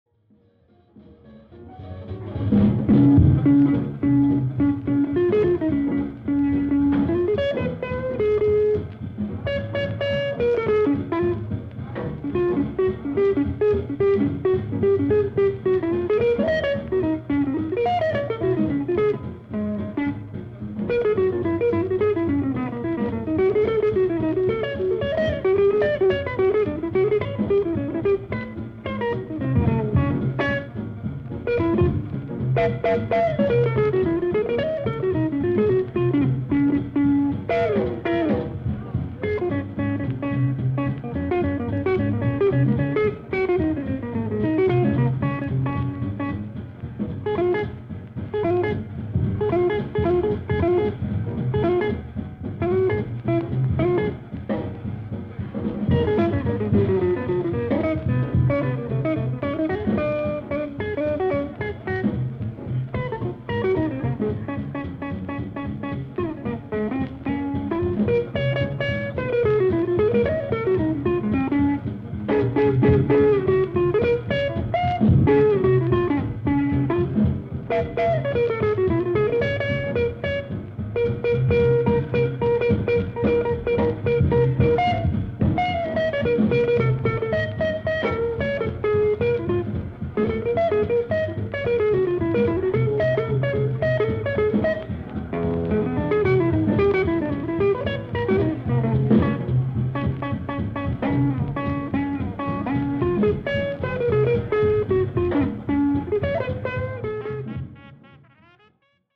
May 12 1941, Minton’s
drums
bass
piano